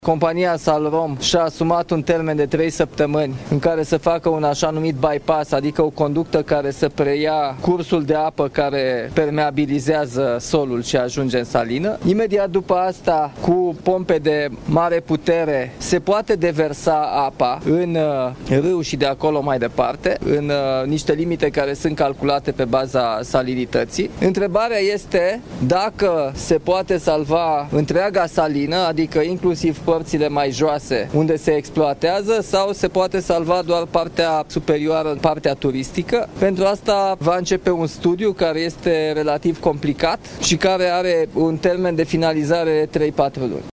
Președintele a mers, sâmbătă, în vizită la Praid, în județul Harghita, unde a discutat cu autoritățile locale despre măsurile ce trebuie luate în perioada următoare.